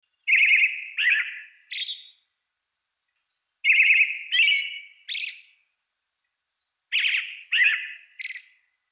アカハラ｜日本の鳥百科｜サントリーの愛鳥活動
「日本の鳥百科」アカハラの紹介です（鳴き声あり）。本州の高原から北海道の山林で繁殖し、明るいひらけた林の地上でミミズや昆虫のえさをとり、冬は暖かい地方の平地へ移動します。